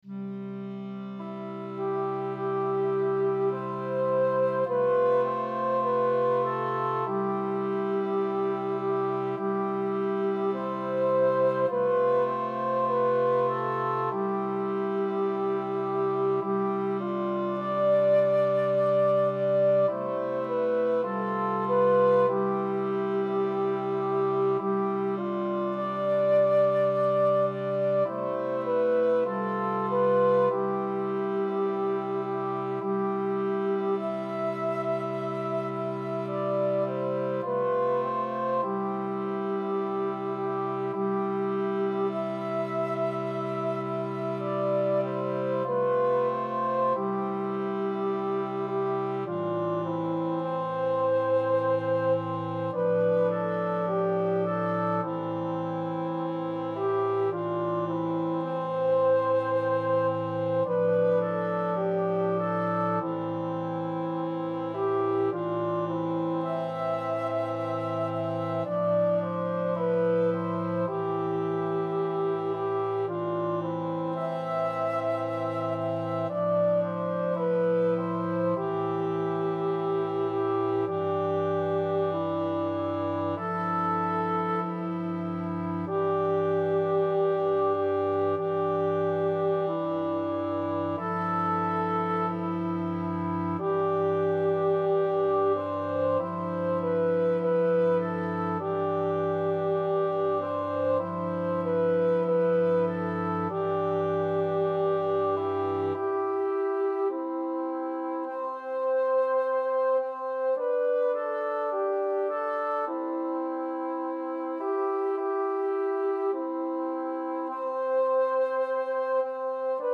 (1986) Chorus